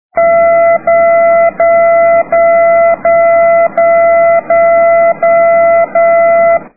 10h00 - çà y est quelqu'un a entendu la balise en direction de l'est. Je branche le récepteur et tourne l'antenne dans tous les sens : effectivement, il y a un faible signal modulé par un piou-piou
pas trés harmonieux mais je l'entends plutôt en direction de l'ouest.